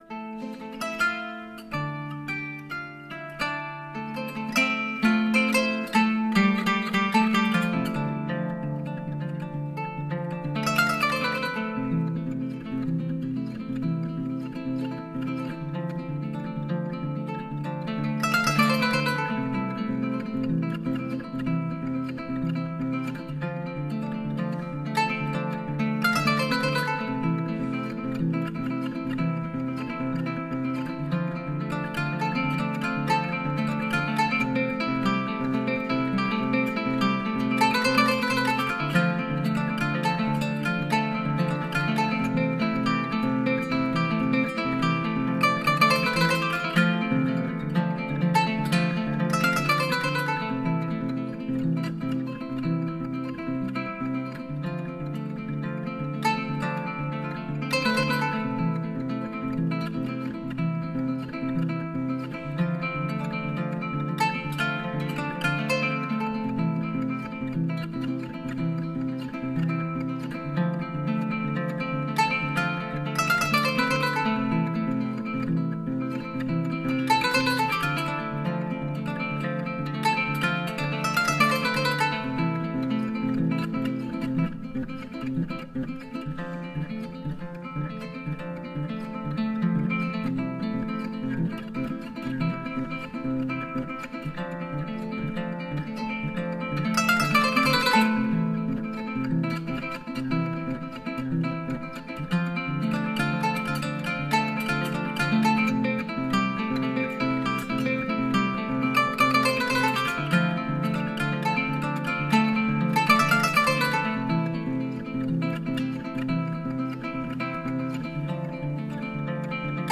Blues en las ondas 500.ogg